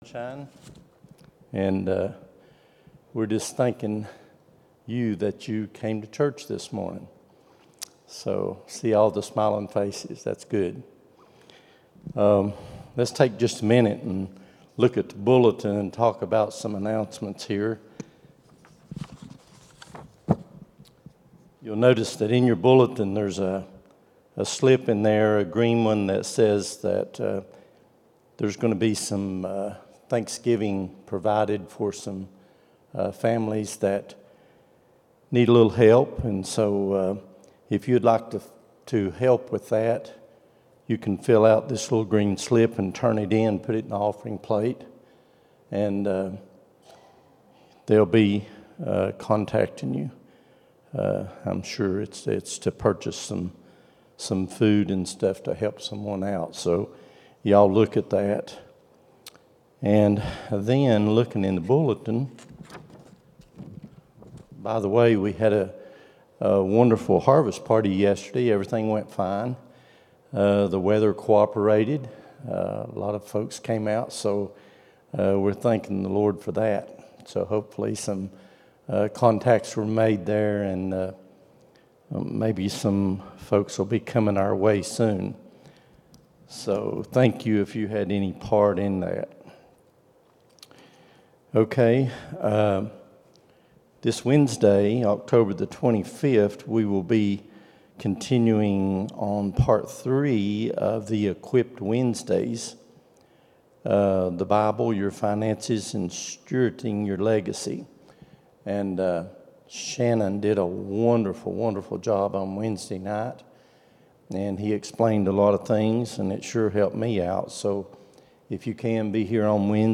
10-22-23 Sunday School | Buffalo Ridge Baptist Church